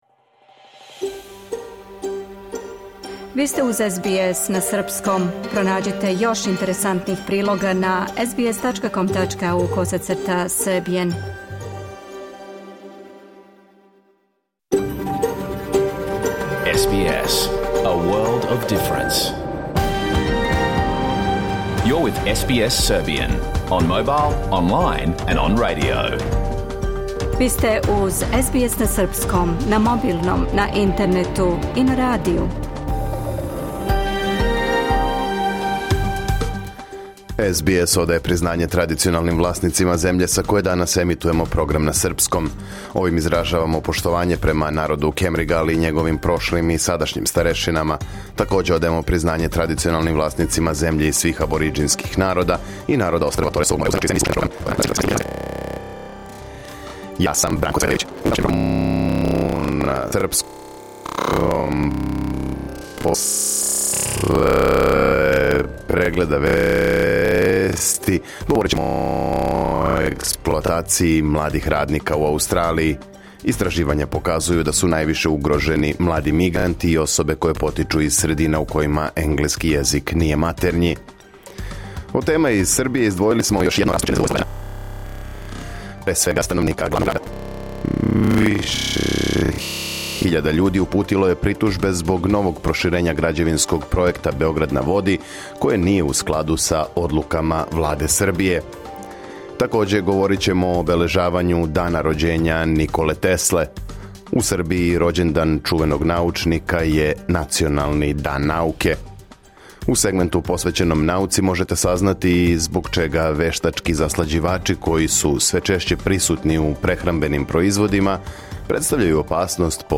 Програм емитован уживо 17. јула 2025. године